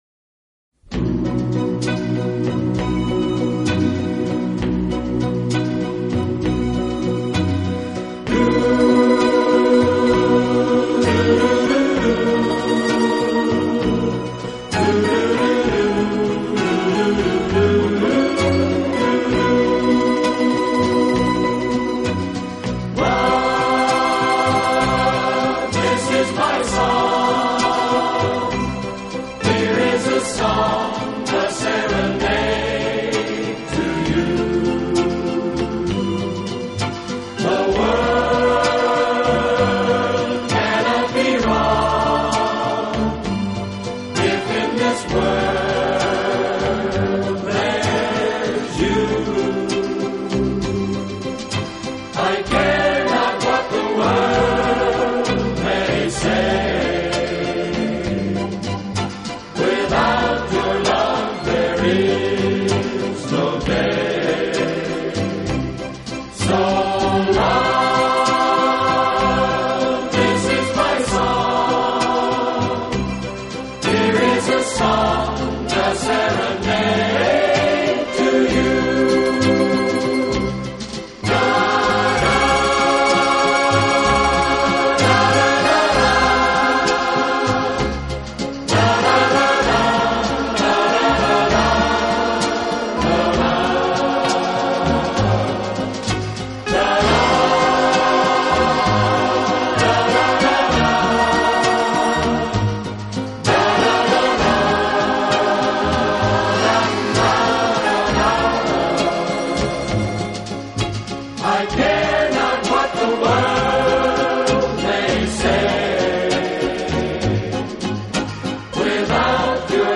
轻音乐专辑
他在60年代以男女混声的轻快合唱，配上轻松的乐队伴奏，翻唱了无数热